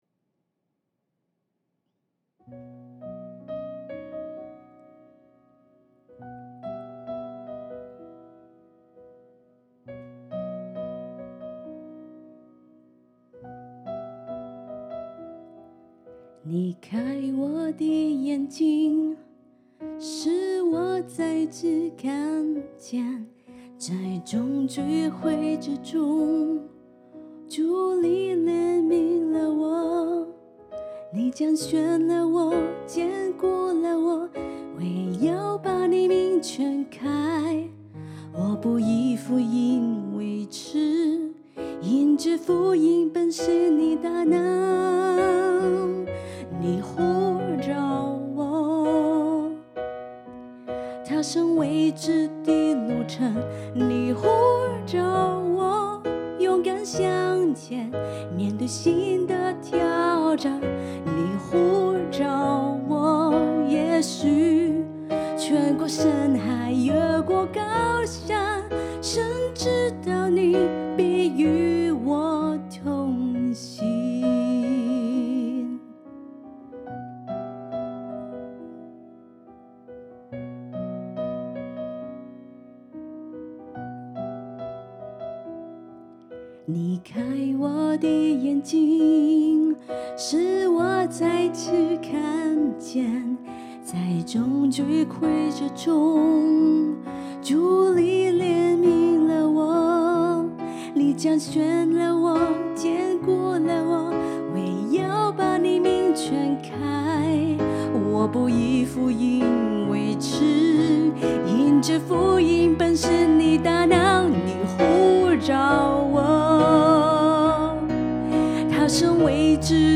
Key C Tempo 66